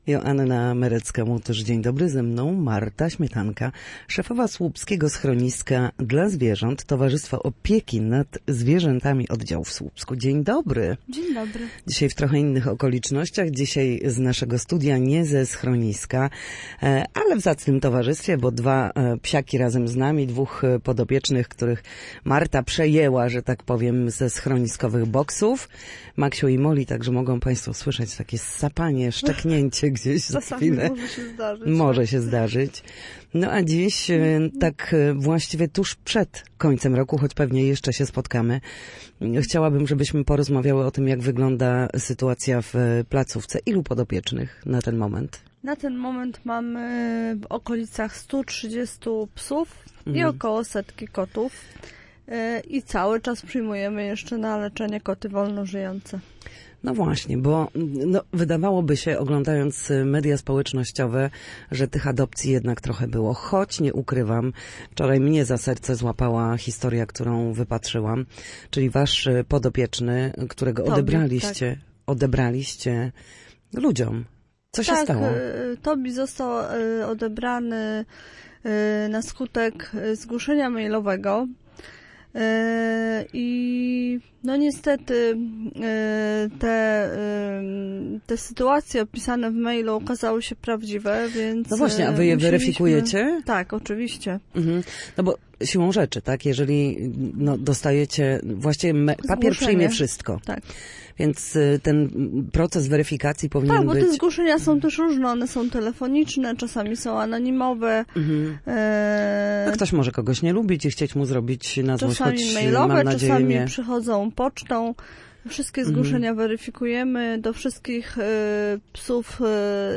Na naszej antenie opowiadała o interwencjach pracowników schroniska, działaniach edukacyjnych oraz o tym, jak zadbać o zwierzęta zimą.